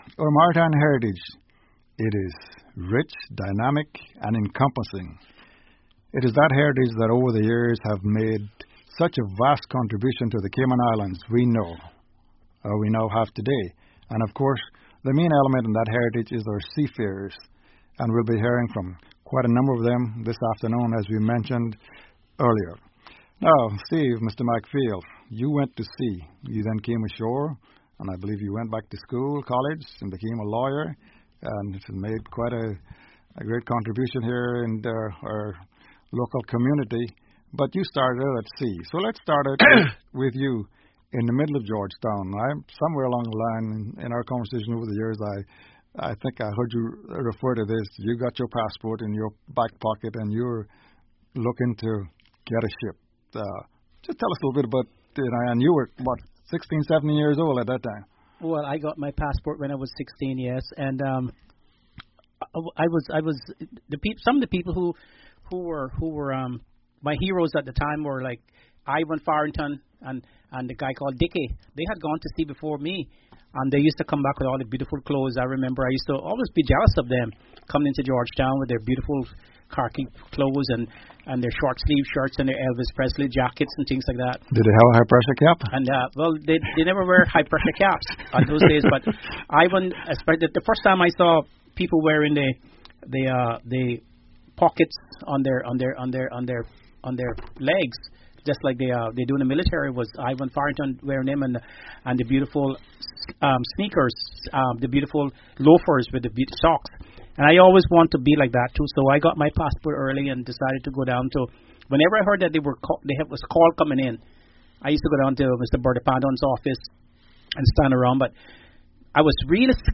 Immerse yourself in the voices of Caymanian seafarers through the Seafarers Registry voice recordings archive. Listen to stories, memories, and experiences shared by the seafarers who played a vital role in the Cayman Islands' maritime history.